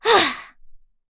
Pansirone-Vox_Sigh.wav